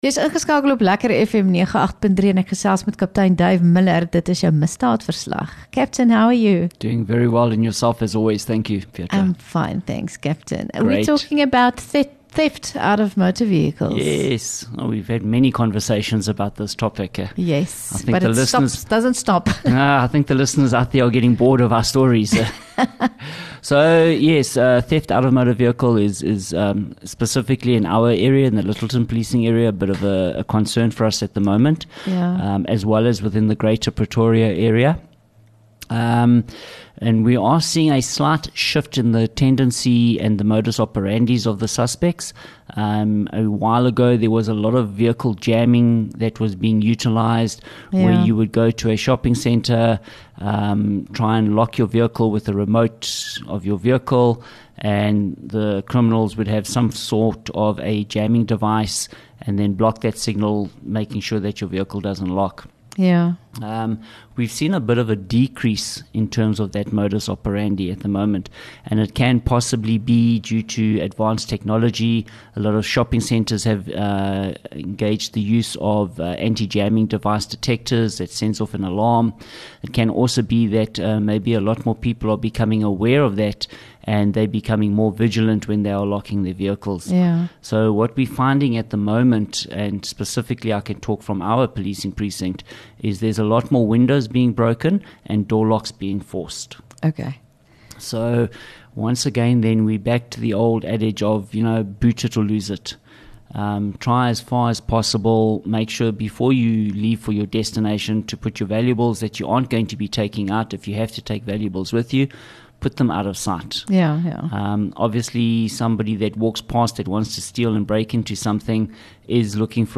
LEKKER FM | Onderhoude 7 May Misdaadverslag